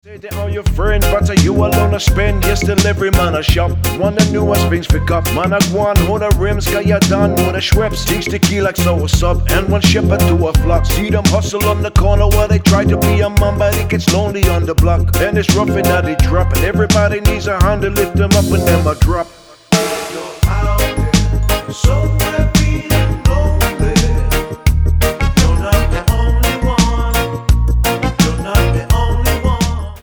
Tonart:Ab Inc. Rap mit Chor